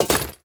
Minecraft Version Minecraft Version snapshot Latest Release | Latest Snapshot snapshot / assets / minecraft / sounds / item / armor / equip_netherite1.ogg Compare With Compare With Latest Release | Latest Snapshot
equip_netherite1.ogg